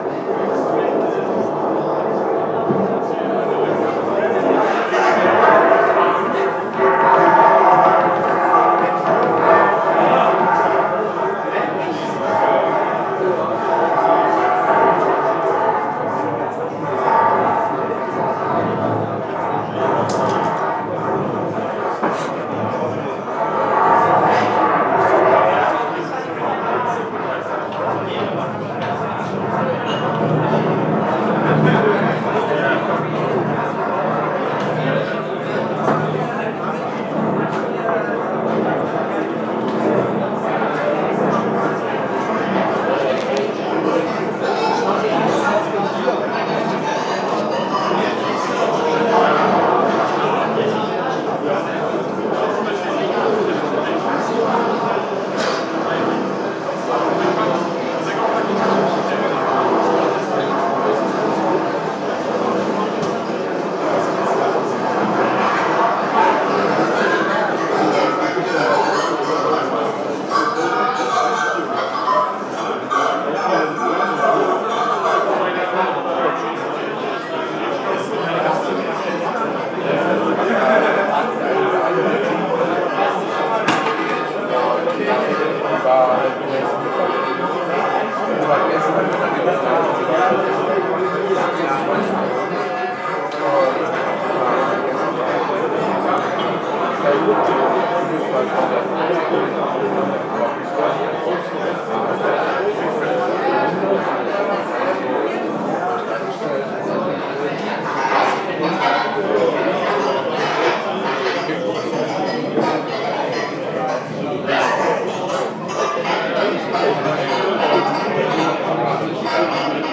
Creepy Noises